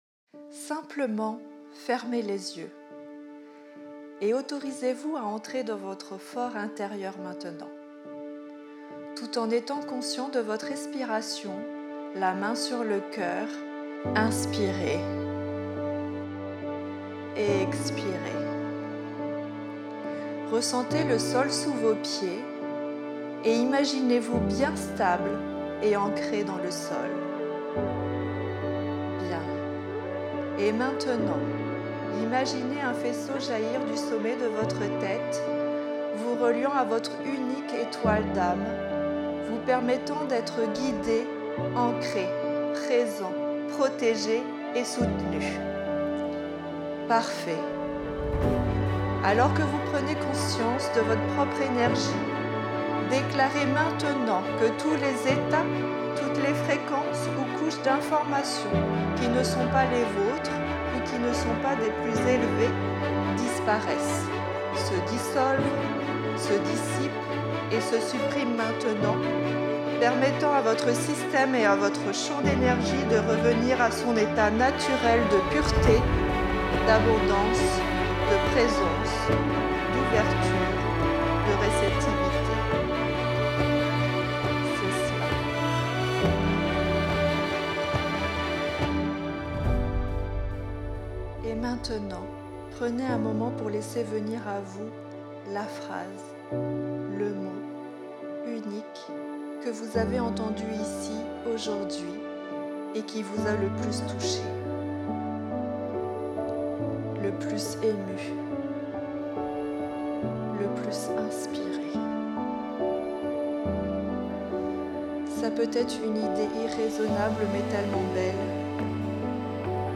Revivez le temps fort de clôture des plénières du Forum Femmes et Challenges 2024 : la Visualisation Forcer le destin !
Femmes-et-Challenges-Voix-musique-V4.wav